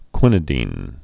(kwĭnĭ-dēn)